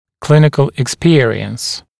[‘klɪnɪkl ɪk’spɪərɪəns] [ek-][‘клиникл ик’спиэриэнс] [эк-]клинический опыт